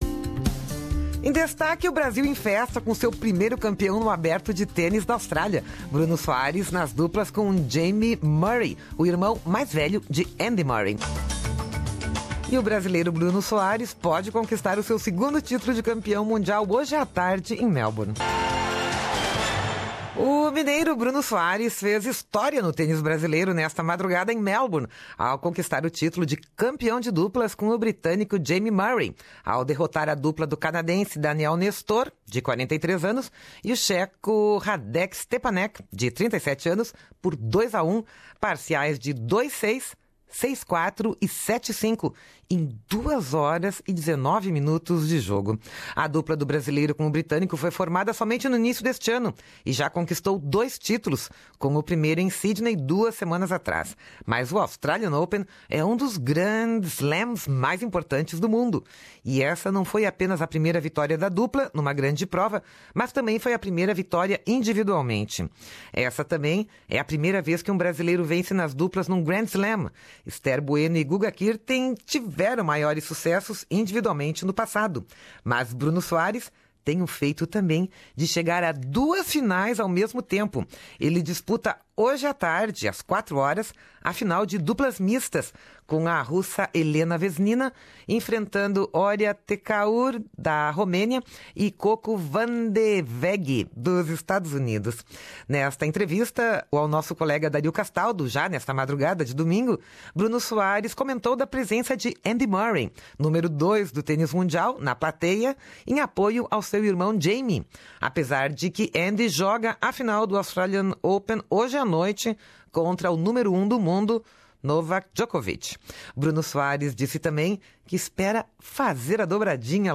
O Brasil está em festa com o seu primeiro campeão no Aberto de Tênis da Austrália. Ouça aqui a reportagem.